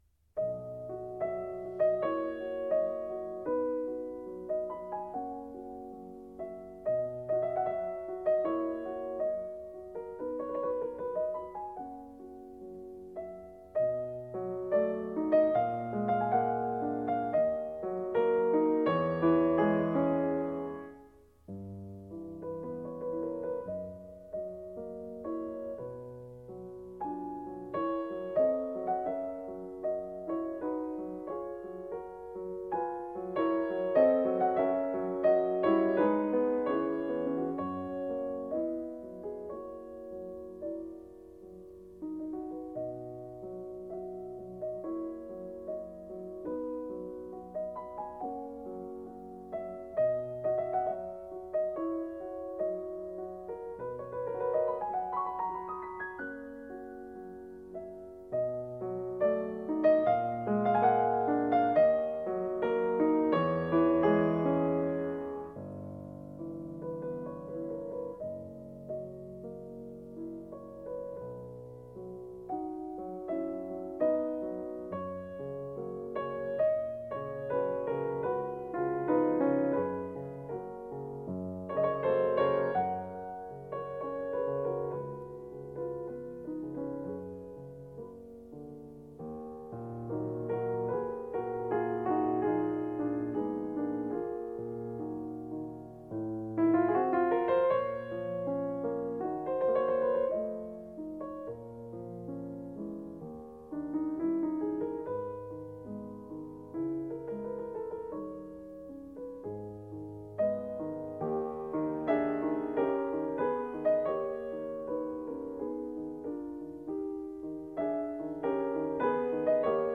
行板，梦一般的纯朴，尾声有暴风雨般的宣叙与之对比。